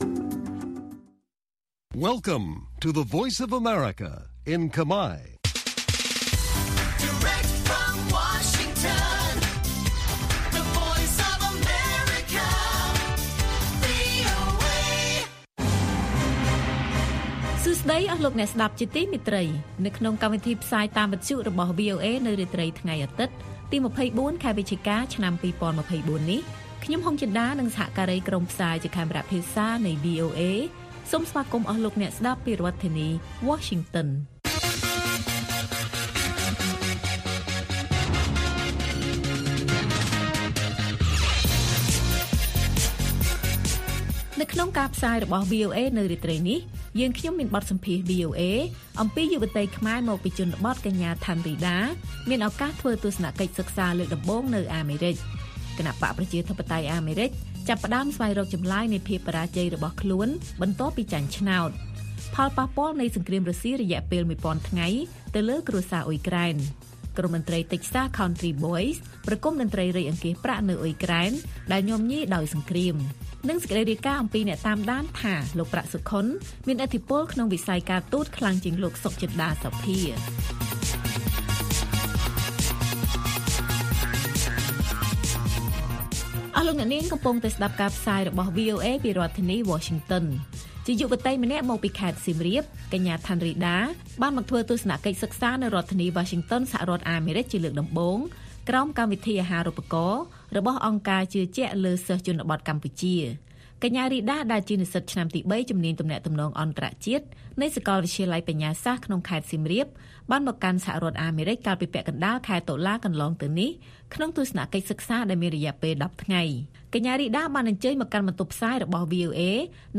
ព័ត៌មានពេលរាត្រី